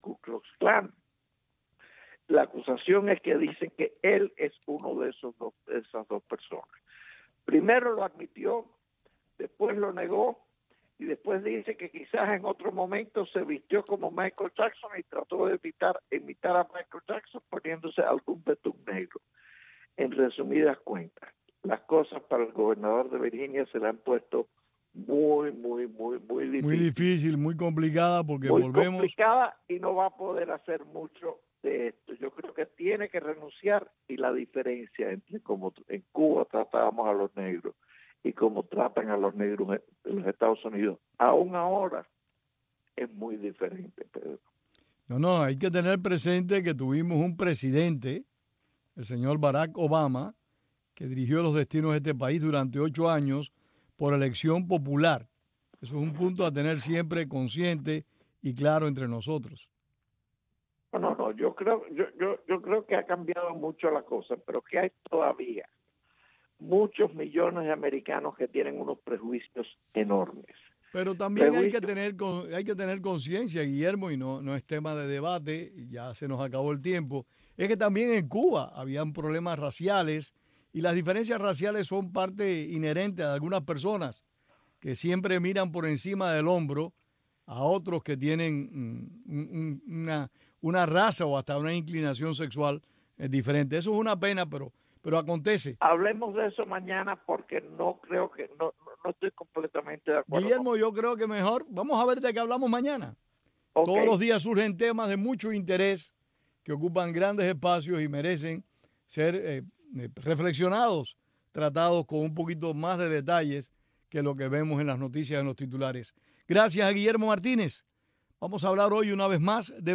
Entrevistamos al ex diputado venezolano Walter Marquez sobre la iminente ayuda humanitaria que esta en la frontera con Colombia y que Maduro no acepta.